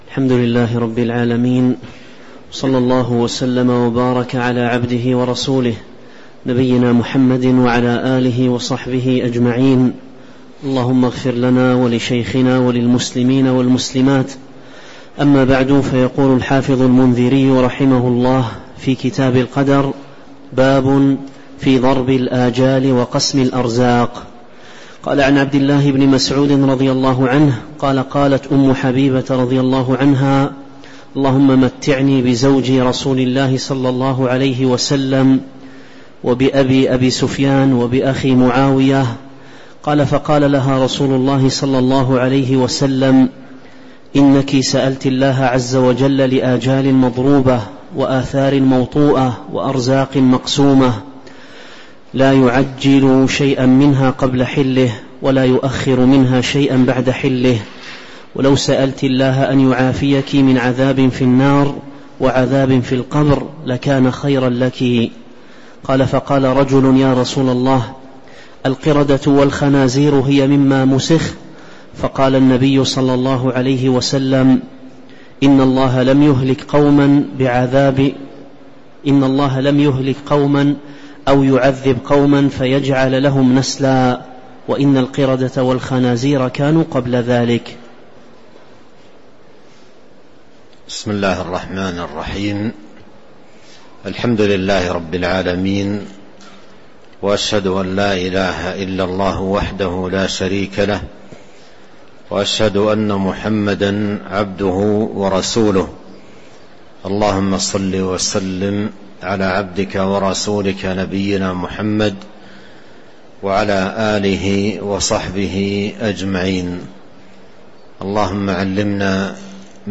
تاريخ النشر ٢٥ ذو القعدة ١٤٤٣ هـ المكان: المسجد النبوي الشيخ: فضيلة الشيخ عبد الرزاق بن عبد المحسن البدر فضيلة الشيخ عبد الرزاق بن عبد المحسن البدر باب في ضرب الآجال وقسم الأرزاق (03)